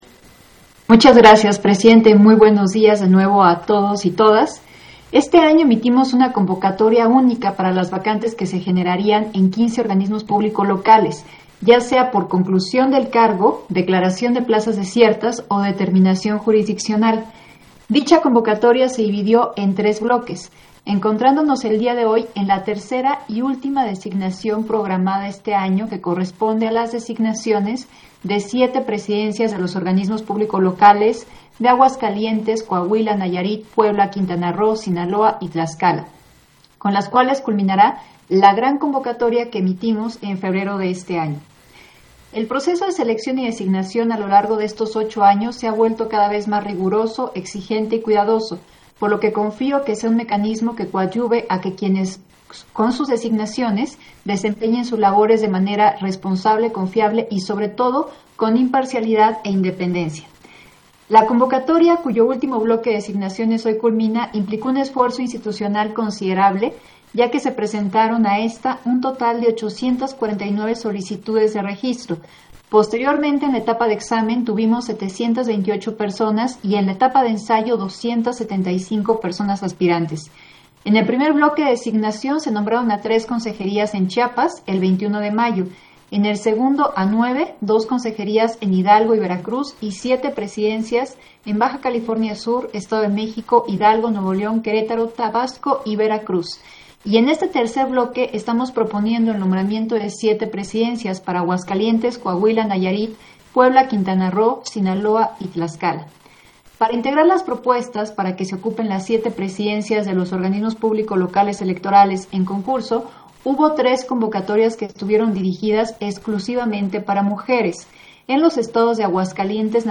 Intervención de Dania Ravel, en Sesión Ordinaria, en el punto en el que aprueba la designación de presidencias de OPL